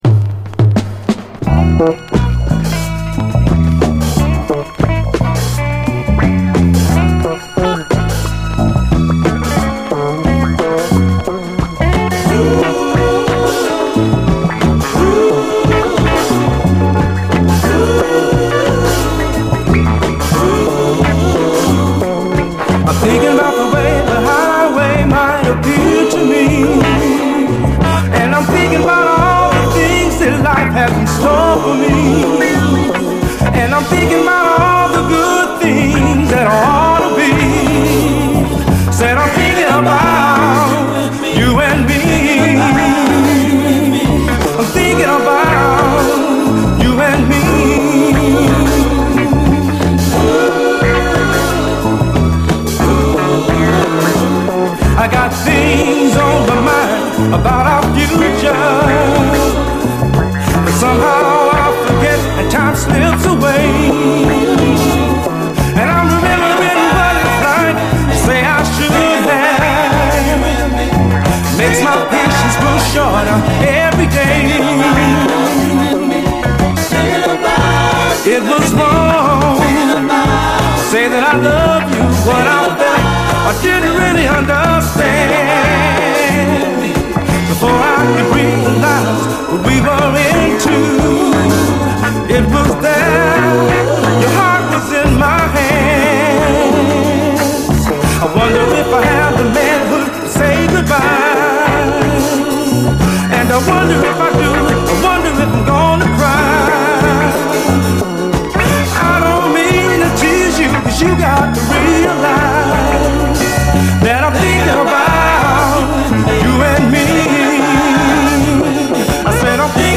お洒落なサルサ